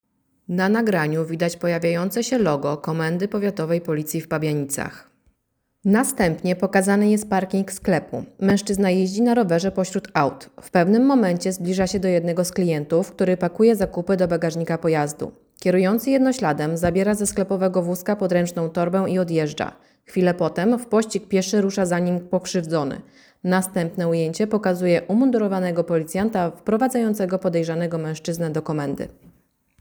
Nagranie audio Audiodeskrypcja nagrania